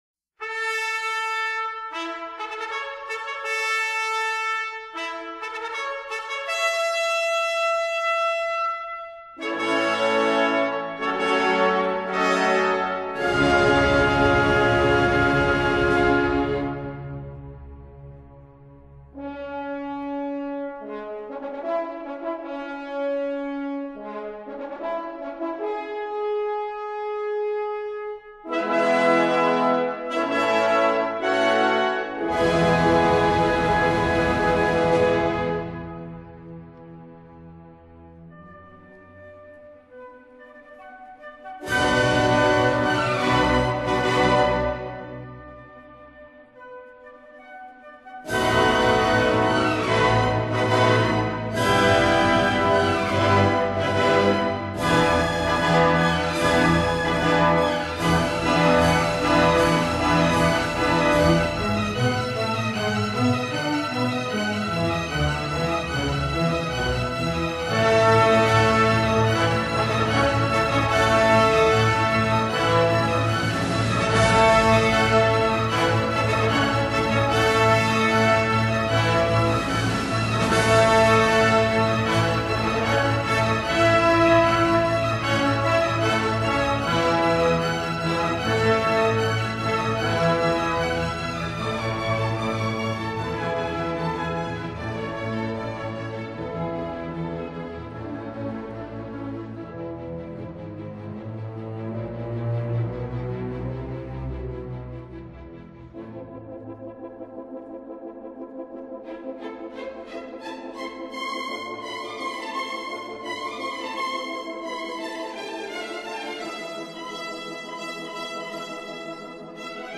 Bordignera, 1.1.2017 - Palazzo del parco: "Concerto di Capodanno" dell'orchestra sinfonica Città di Bordighera
I pezzi migliori sono stati l'Ouverture dalla